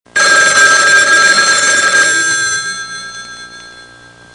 Columbo-Klingelton
Es ist das typische "Krrrrring" wie in den Columbo-Folgen wenn das Telefon klingelt.
columbo-telefon.mp3